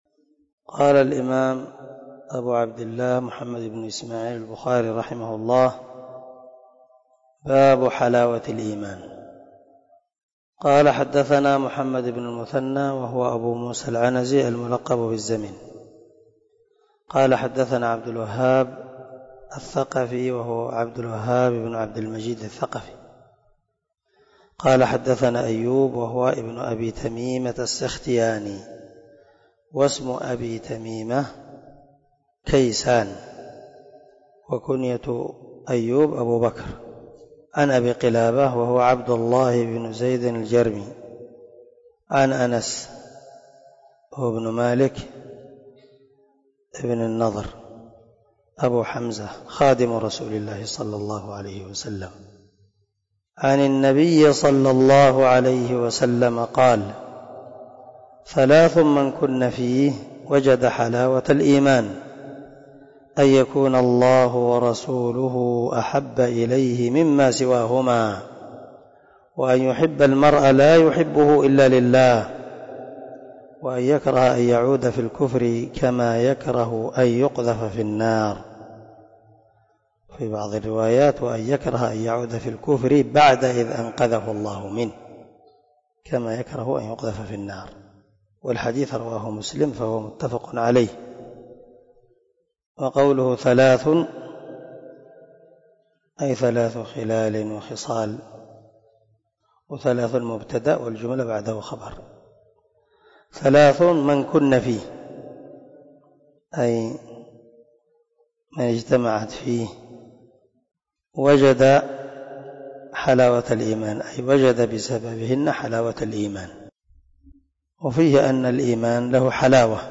018الدرس 8 من شرح كتاب الإيمان حديث رقم ( 16 ) من صحيح البخاري